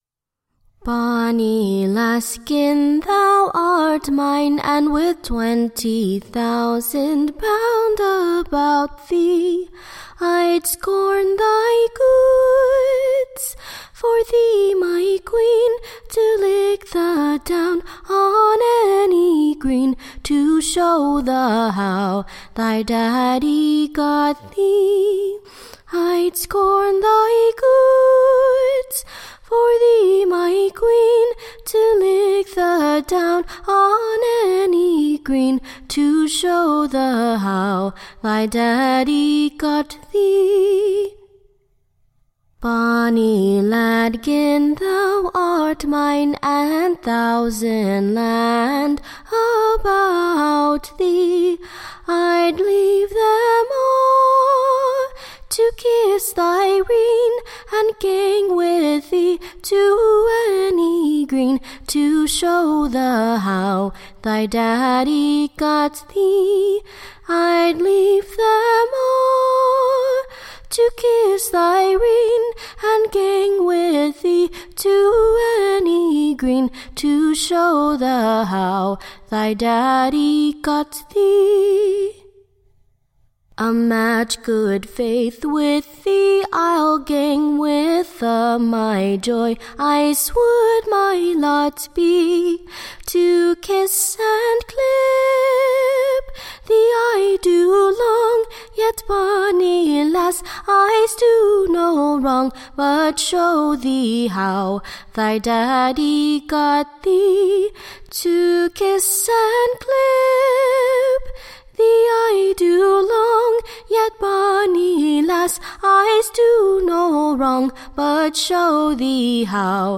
Recording Information Ballad Title The Longing Virgins Choice: / OR, / The Scotch Lasses Delight.
Tune Imprint To a Modish new Scoth Tune, Sung at the Dukes Playhouse.